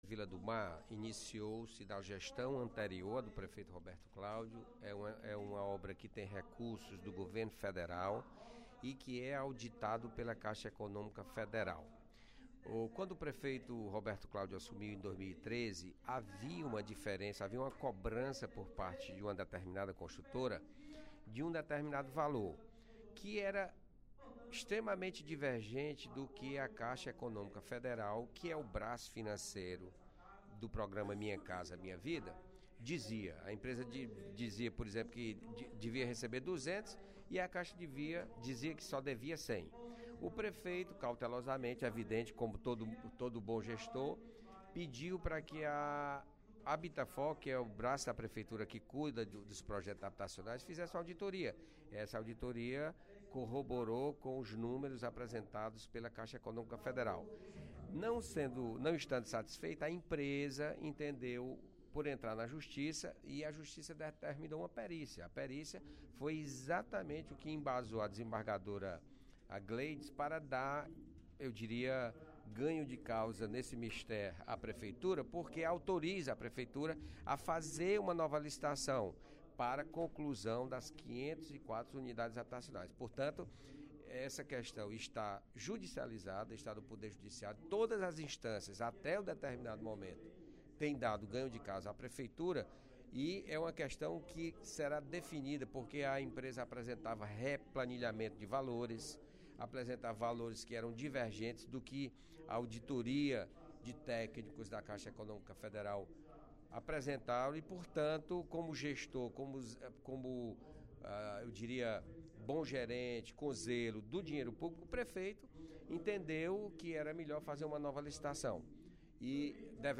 O deputado José Sarto (Pros) procurou responder, durante o primeiro expediente da sessão plenária desta quarta-feira (02/09), ao deputado Heitor Férrer (PDT), que apontou, em sua fala, supostas irregularidades na execução das obras da Vila do Mar III. A obra estaria paralisada por falta de pagamento da Prefeitura.
Em aparte, o deputado Roberto Mesquita (PV) disse que causa estranheza a querela.